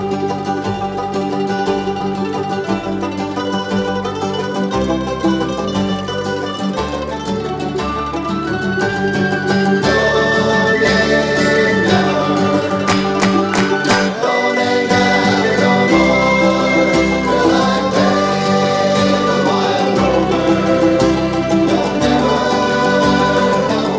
Irish